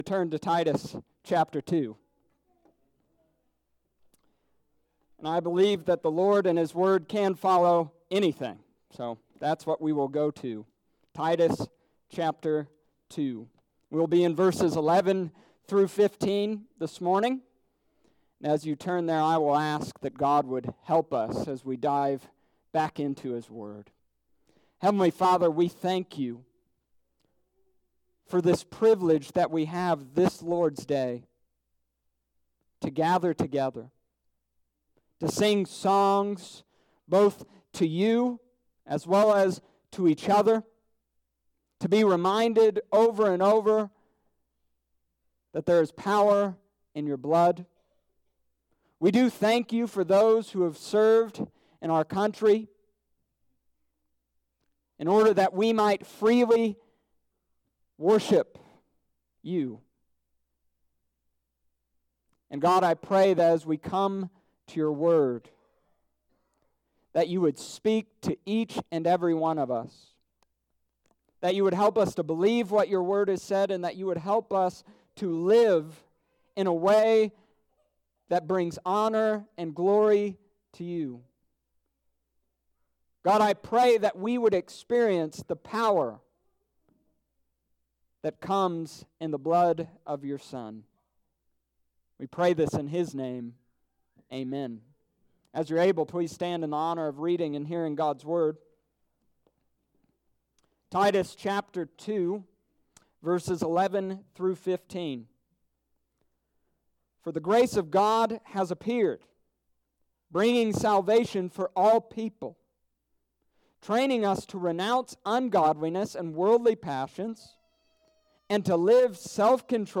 Summary of Sermon: This week, we continued the book of Titus.